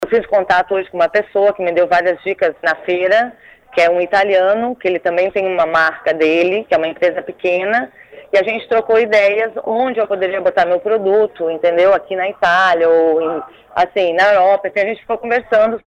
que conversou por telefone, de Milão, com o Portal da Indústria.